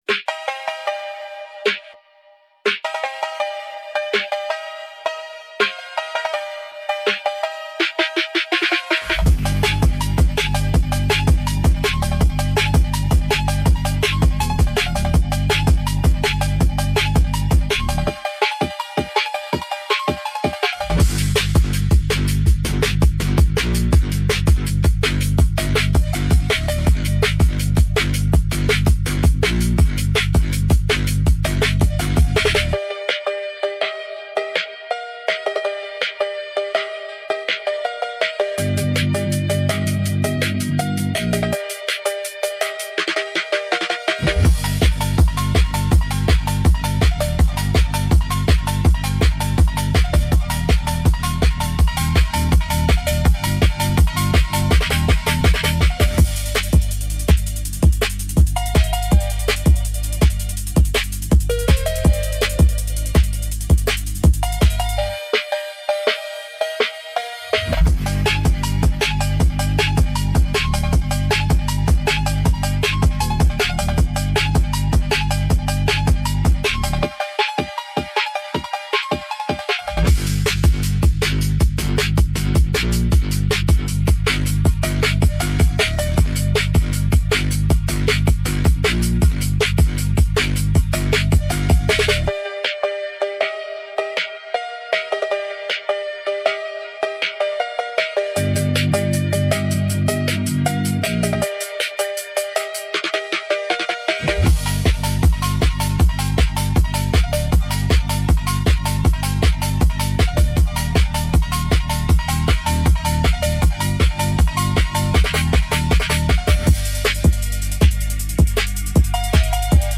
There are no lyrics because it is an instrumental.